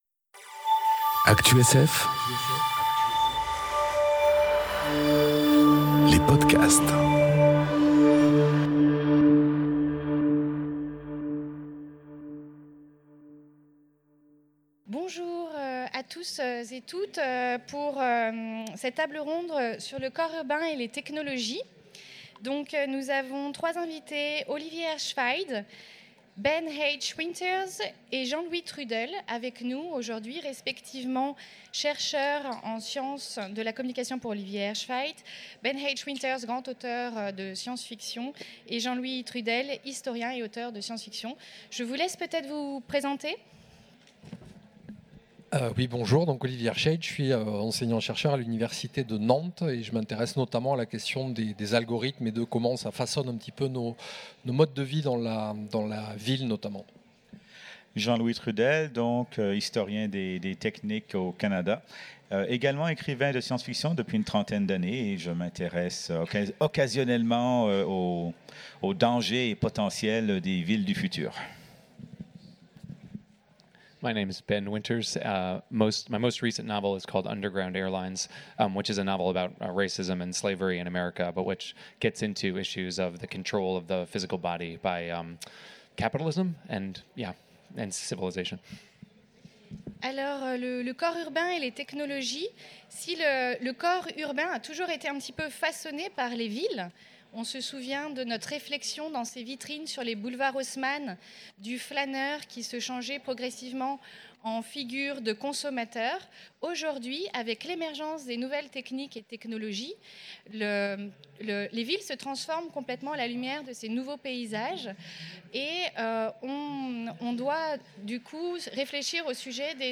Conférence Corps urbain et technologies enregistrée aux Utopiales 2018